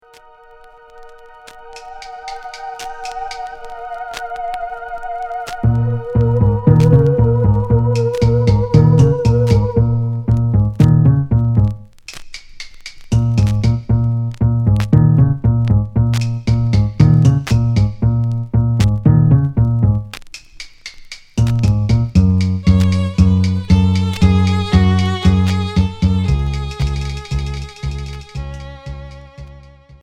Cold pop